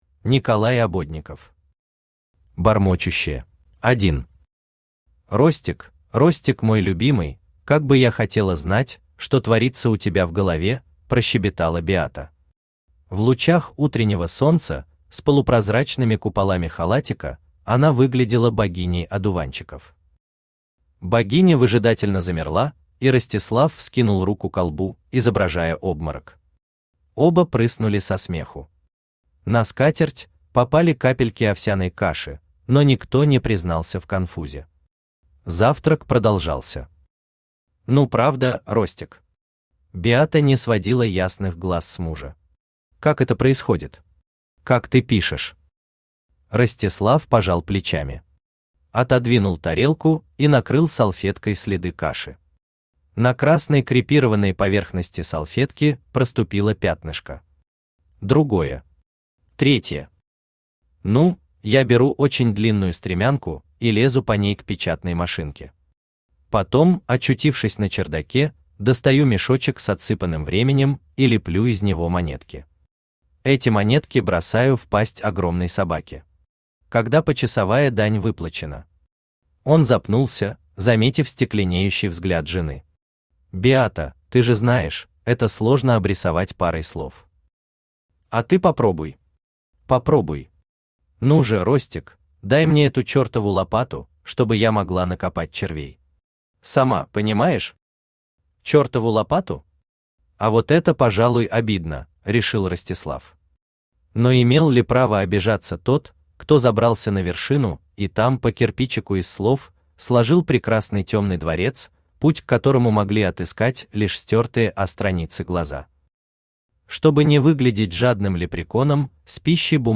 Аудиокнига Бормочущие | Библиотека аудиокниг
Aудиокнига Бормочущие Автор Николай Ободников Читает аудиокнигу Авточтец ЛитРес.